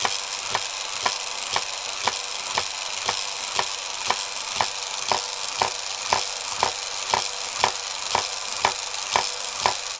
SFX电流通过电路短路嗞嗞交互游戏提示音效下载
这是一个免费素材，欢迎下载；音效素材为电流通过电路短路嗞嗞交互音效游戏提示音效， 格式为 mp3，大小1 MB，源文件无水印干扰，欢迎使用国外素材网。